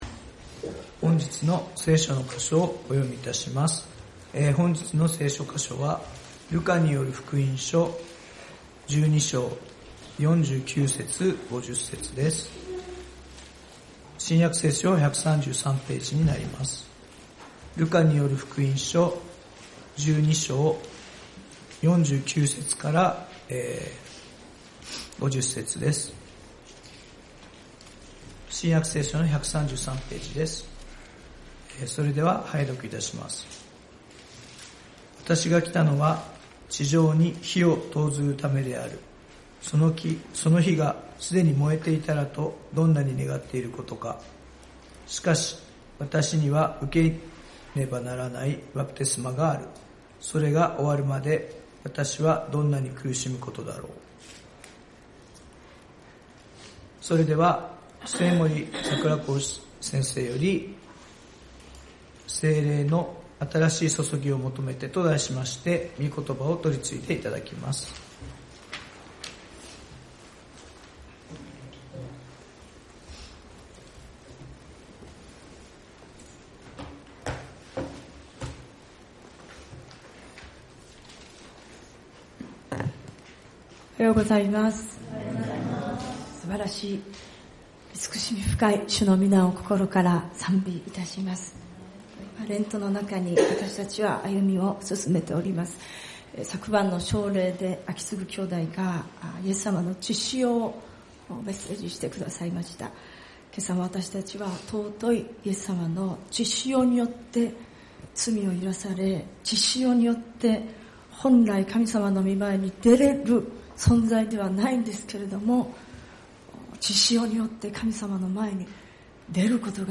聖日礼拝「聖霊の新しい注ぎを求めて」ルカによる福音書12:49-50